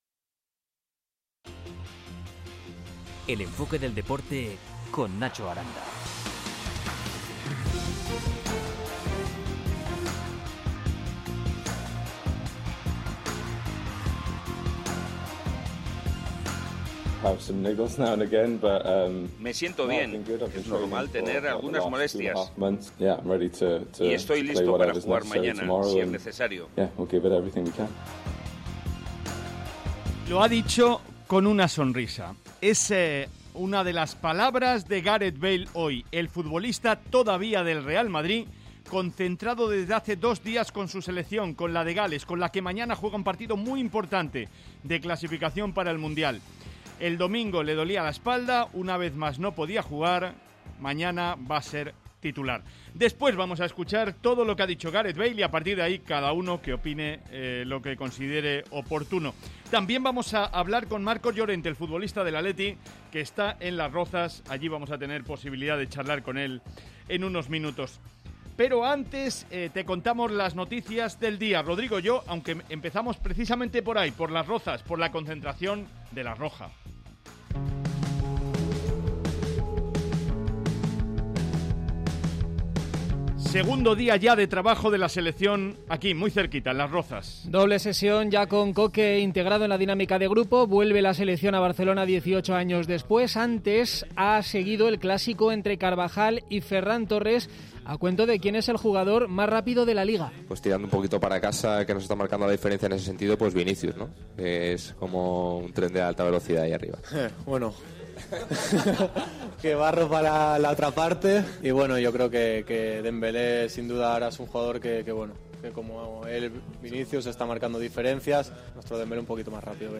Marcos Llorente es el invitado de lujo de El Enfoque Deportes.